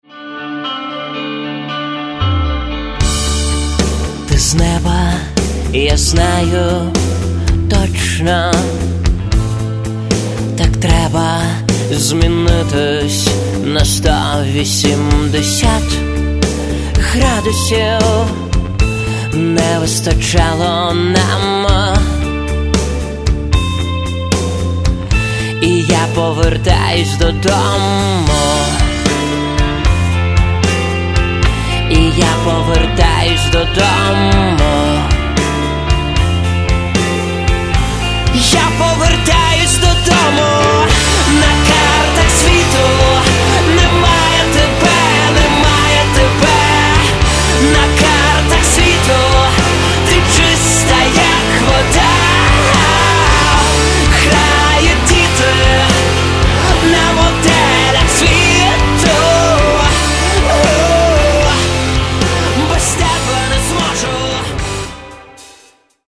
Каталог -> Рок та альтернатива -> Поп рок
цілком якісний гітарний поп-рок.
високий, з барвами інтимної м'якості і відчуттям крихкості.